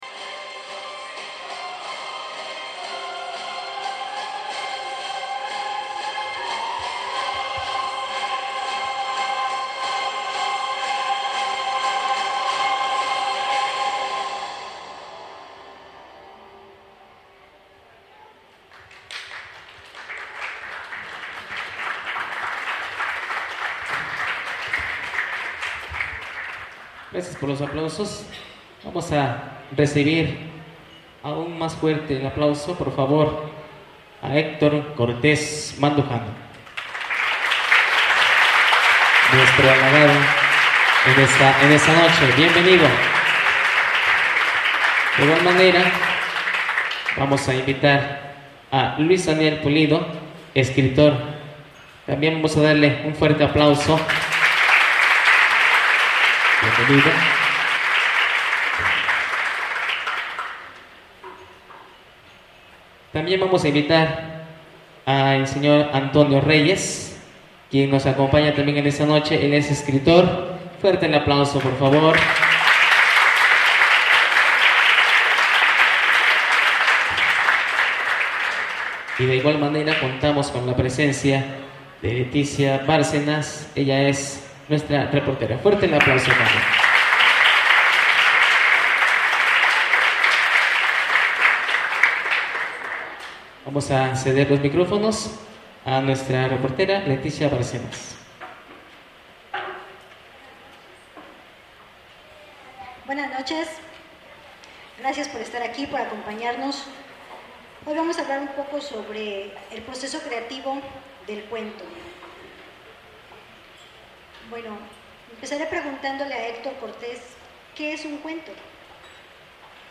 Los invitamos a disfrutar de la presentación de este libro que se llevó a cabo el pasado viernes en la rectoría de la Universidad de Ciencias y Artes de Chiapas, gracias a la gestoría de los emprendedores alumnos de la carrera de Gestión y Promoción de las Artes 3.
Equipo: Grabadora Sony ICD-UX80 Stereo Fecha: 2009-10-22 23:27:00 Regresar al índice principal | Acerca de Archivosonoro